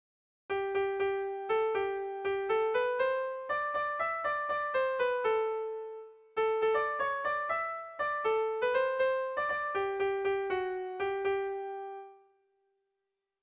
Melodías de bertsos - Ver ficha   Más información sobre esta sección
Kopla handia
ABD